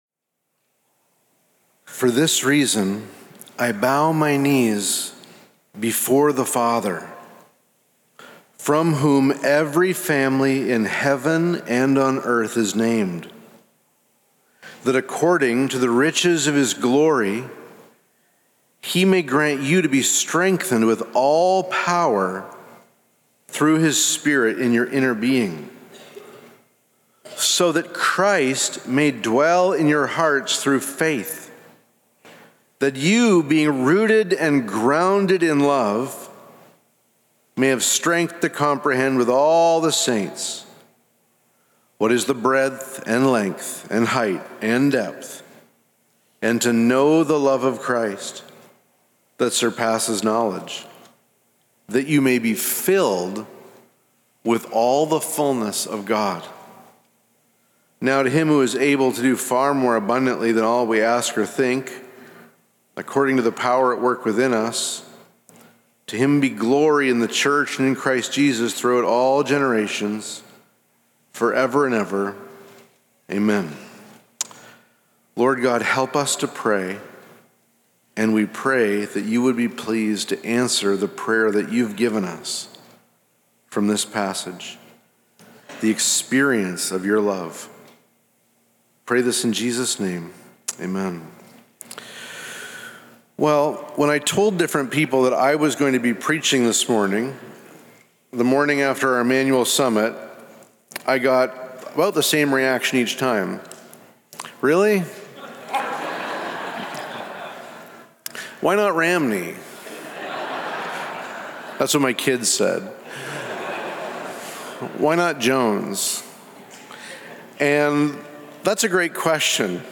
Sermons | Immanuel Baptist Church